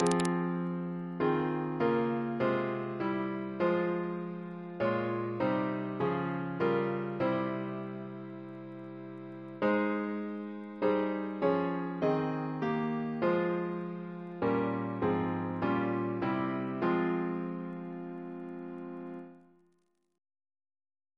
CCP: Chant sampler
Double chant in F minor Composer: Frederick W. Wadely (1882-1970) Reference psalters: ACB: 229